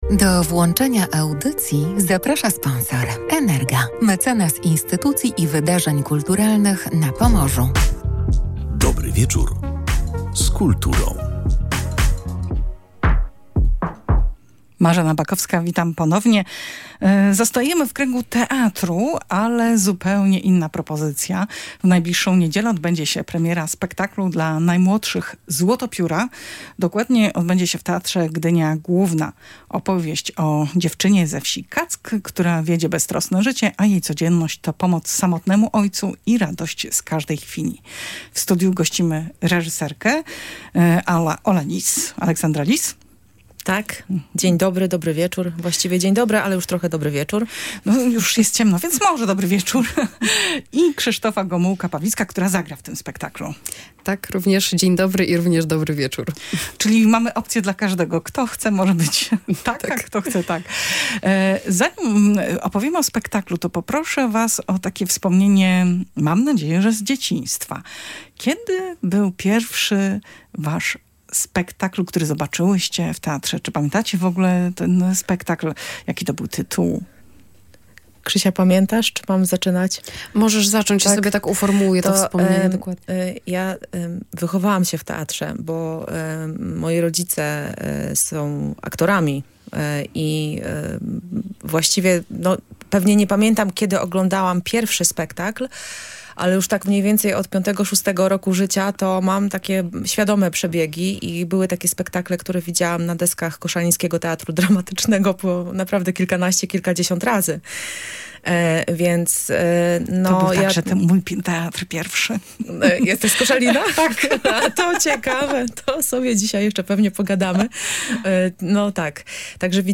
mówiły podczas audycji na żywo